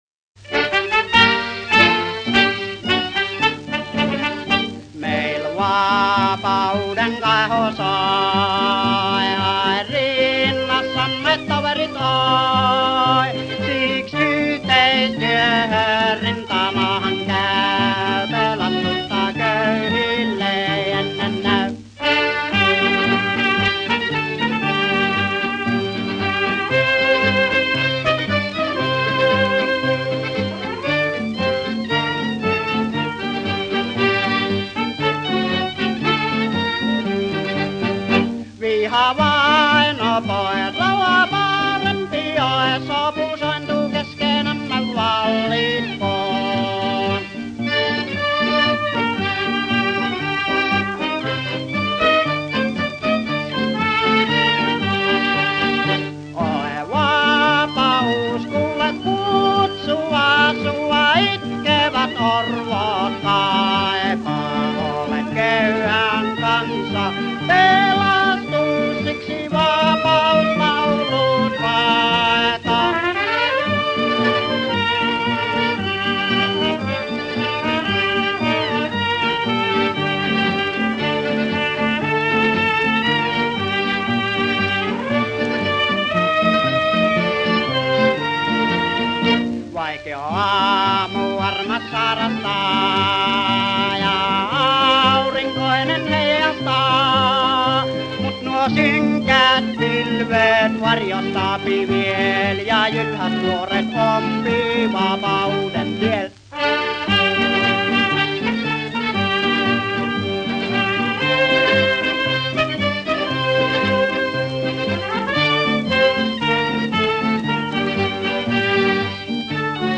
Sänger